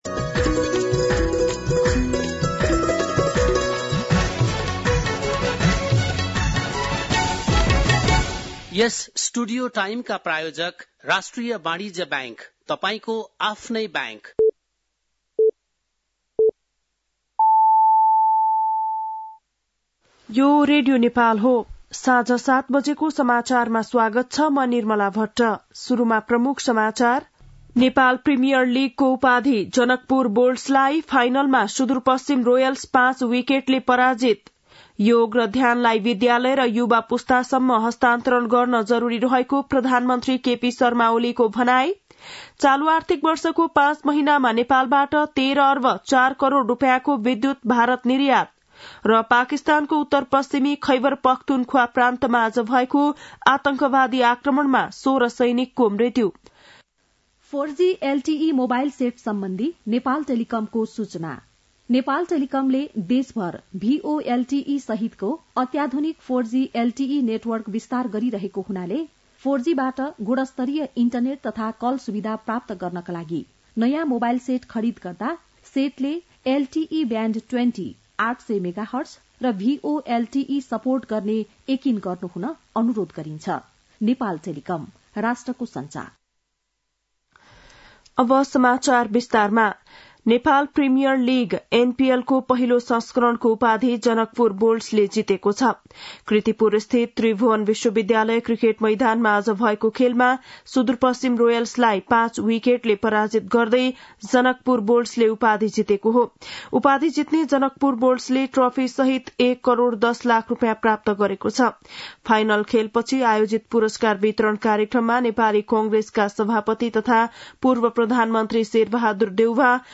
बेलुकी ७ बजेको नेपाली समाचार : ६ पुष , २०८१
7-PM-Nepali-News-9-6.mp3